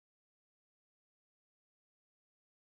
Video Game Twitch Alert Success
positive-win-game-sound-4_oAKBP7b.mp3